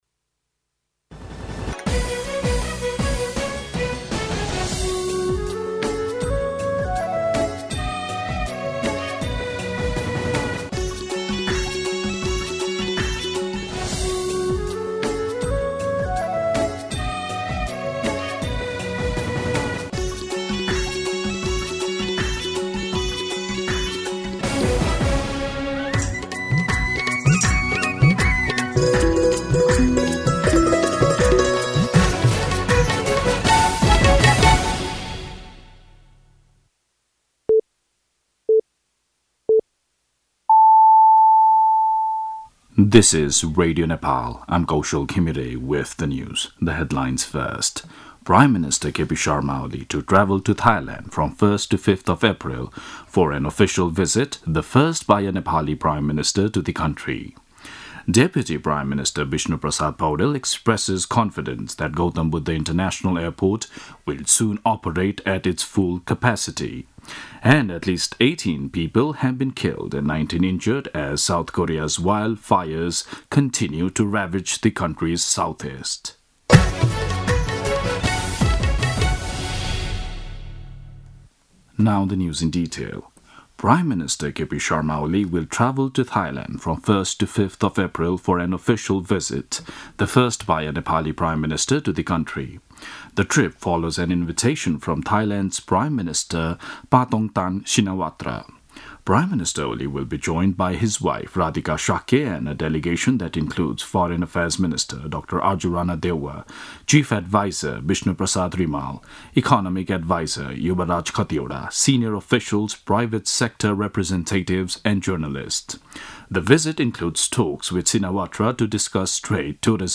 दिउँसो २ बजेको अङ्ग्रेजी समाचार : १३ चैत , २०८१
2pm-English-News-13.mp3